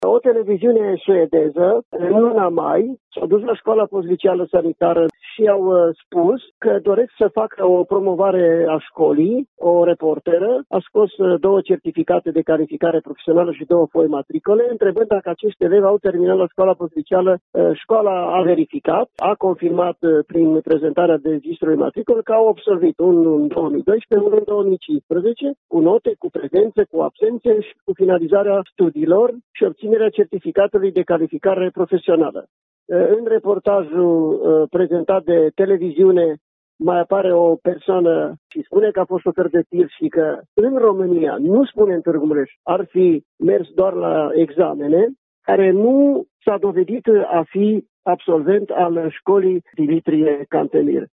Șeful Insectoratului Școlar Județean Mureș, Ioan Macarie s-a deplasat ieri la școala tîrgumureșeană și a aflat că într-adevăr jurnaliștii suedezi s-au interesat despe doi foști absolvenți după ce au intrat în școală sub un alt pretext: